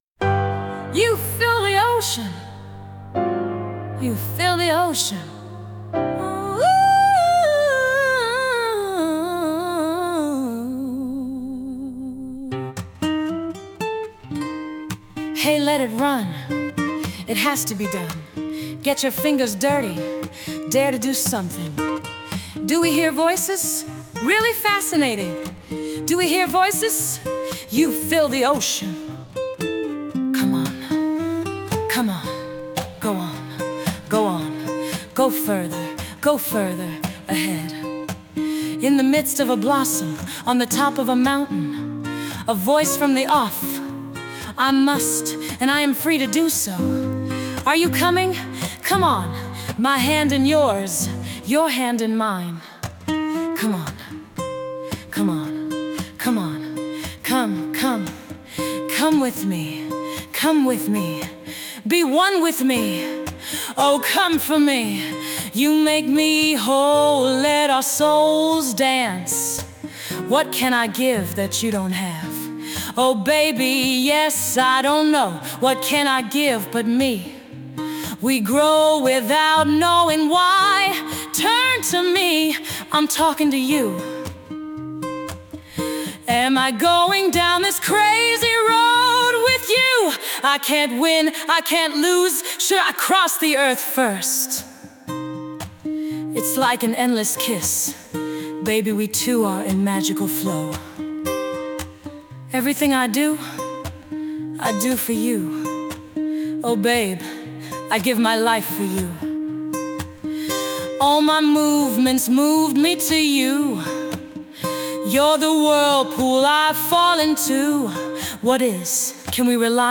Bis mir aber endlich die english idea kam, ficht ich erstmal einen schier aussichtlosen Kampf mit  der KI.